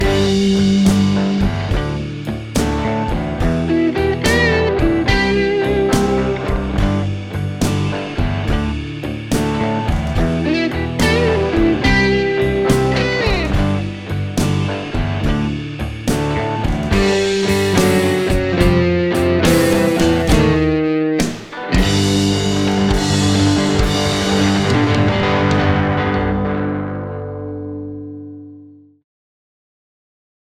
gitara elektryczna + perkusja + bas (full band blues)